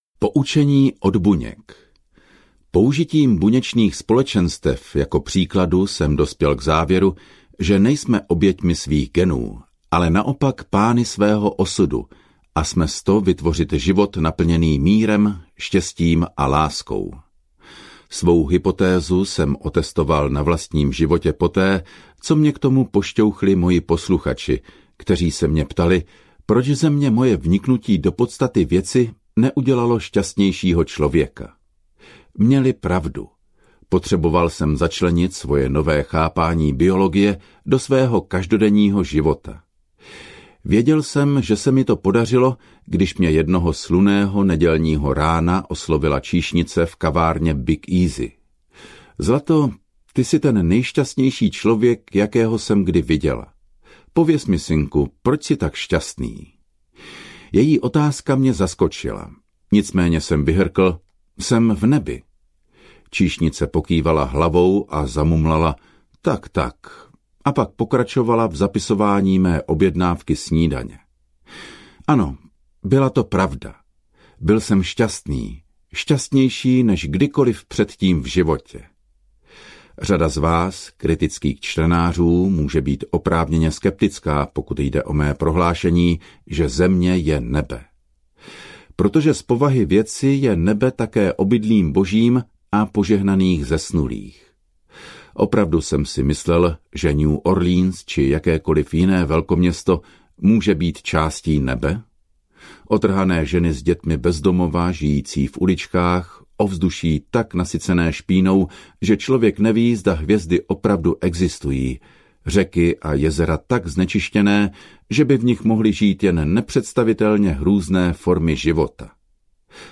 Ukázka z knihy
biologie-viry-jak-uvolnit-silu-vedomi-hmoty-a-zazraku-audiokniha